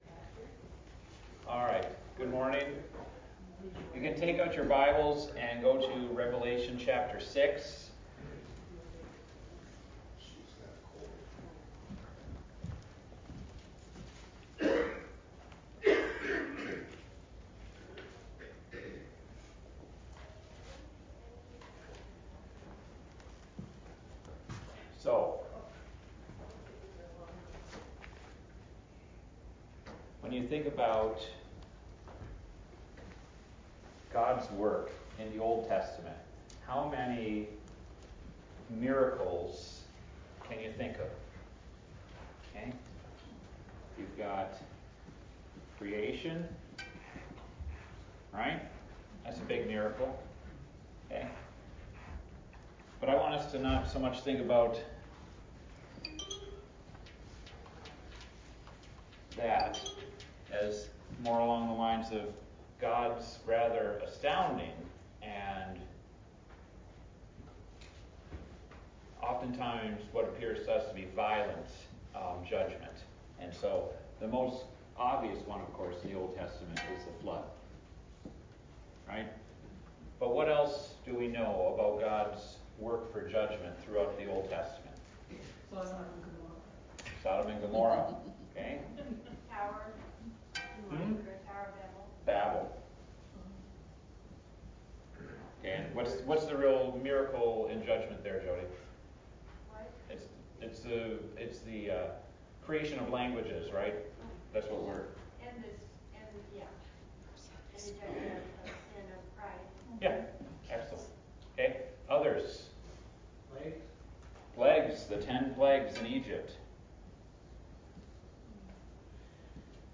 Adult Sunday School A Study in Revelation